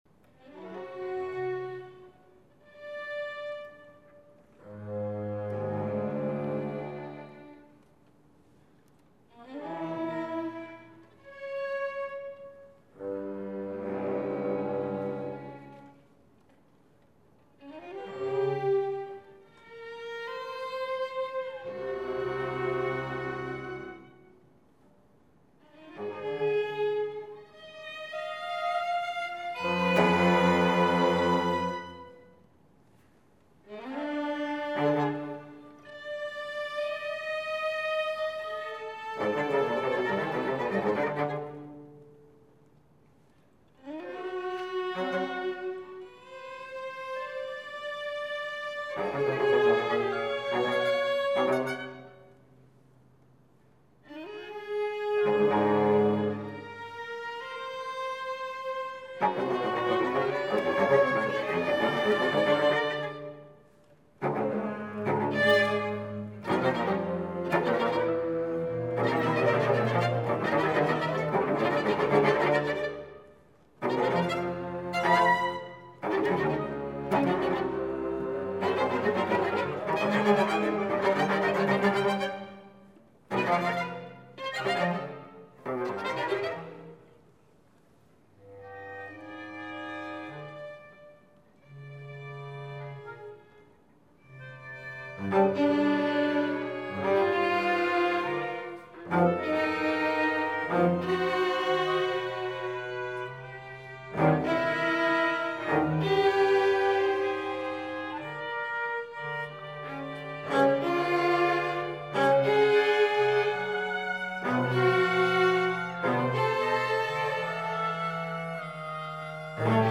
audioMay 12 2005 Brooklyn College Conservatory Orchestra Reading